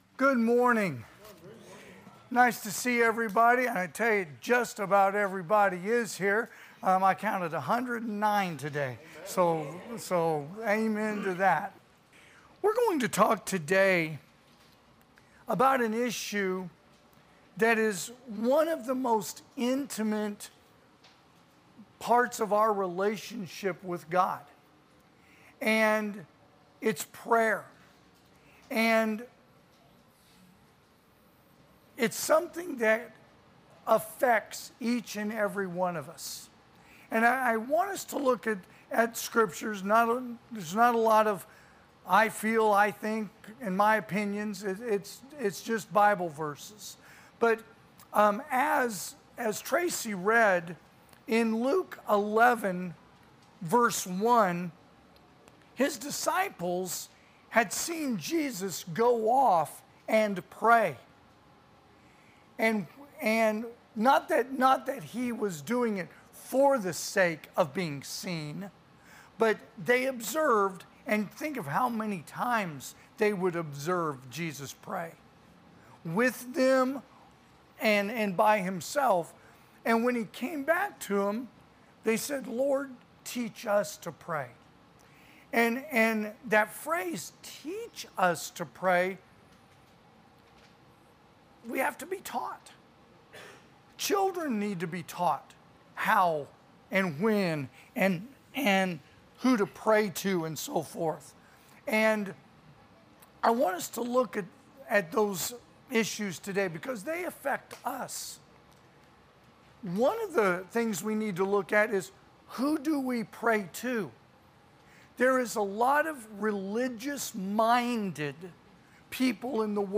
2025 (AM Worship) “Prayer”